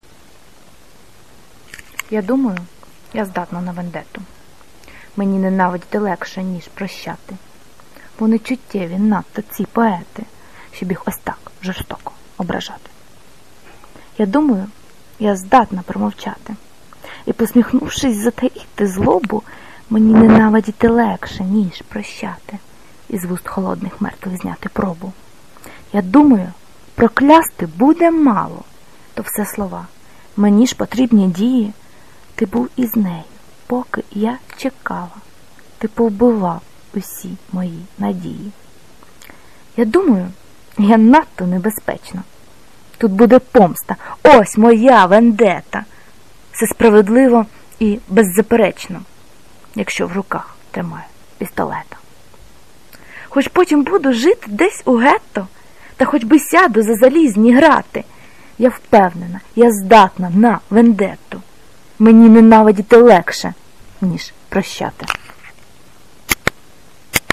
Вендетта (+диктофон)
Рубрика: Поезія, Лірика